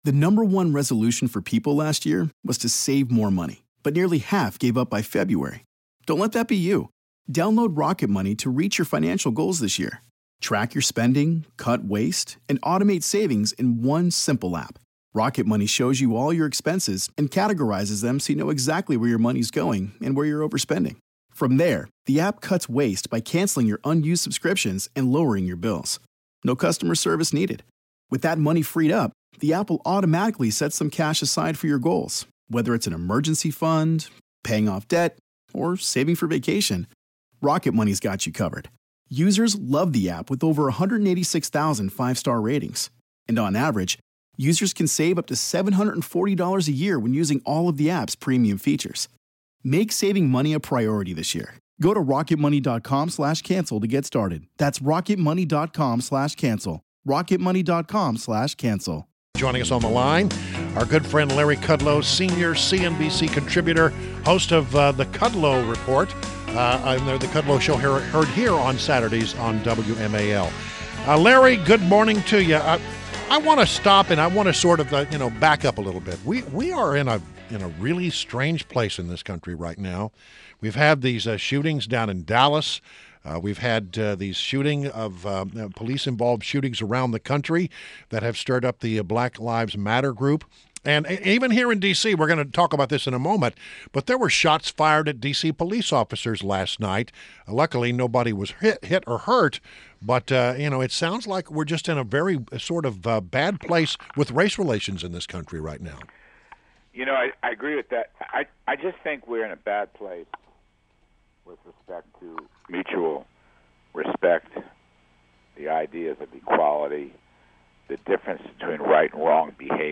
WMAL Interview - Larry Kudlow - 07.12.16